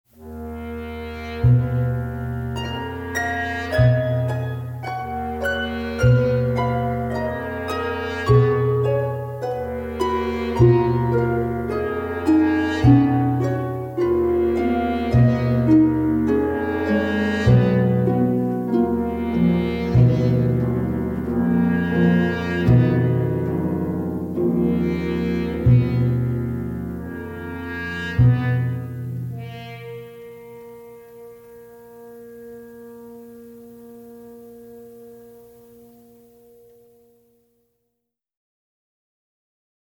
minimal, obsessive style
in clean monaural sound